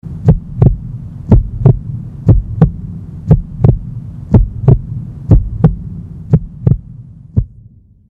Звук как стучит сердце